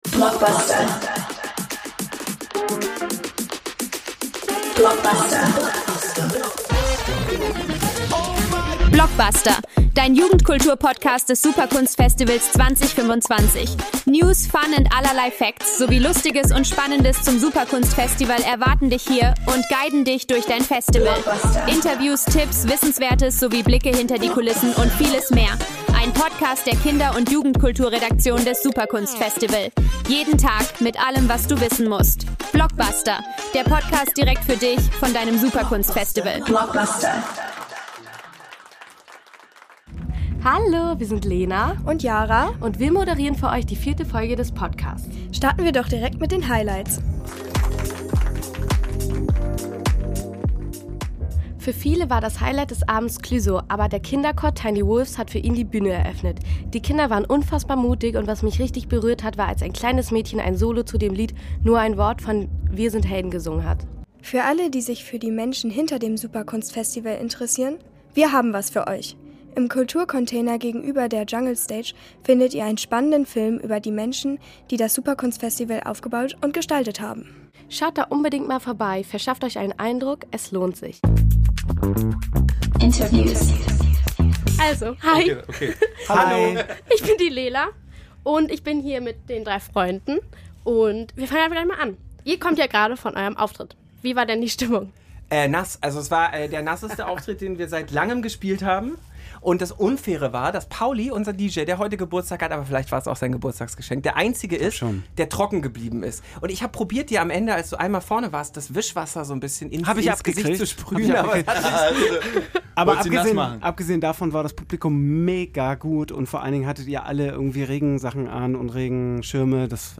Beschreibung vor 9 Monaten In unser letzten Folge zum SUPERKUNSTFESTIVAL 2025 erwartet euch: Ein exklusives Interview mit DEINE FREUNDE direkt nach ihrem Auftritt auf der Mainstage.